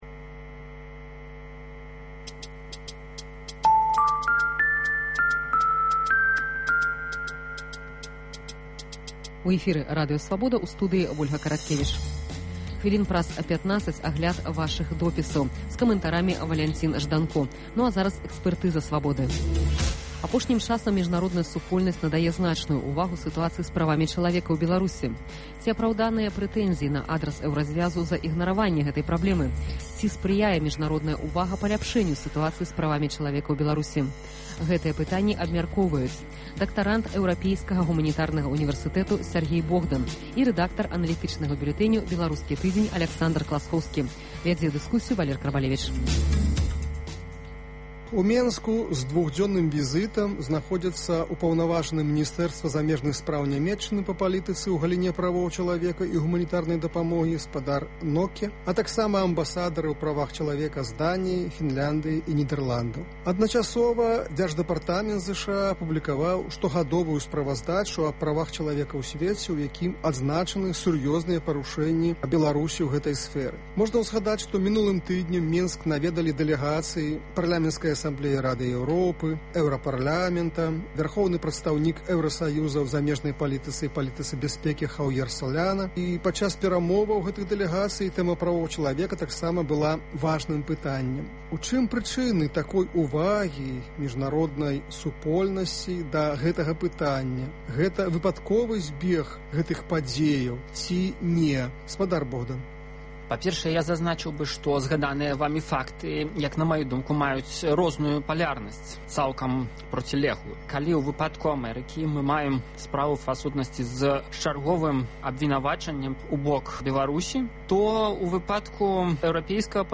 Ці апраўданыя папрокі на адрас Эўразьвязу за ігнараваньне гэтай праблемы? Ці садзейнічае міжнародная ўвага паляпшэньню сытуацыі з правамі чалавека ў Беларусі? Гэтыя пытаньні абмяркоўваюць за круглым сталом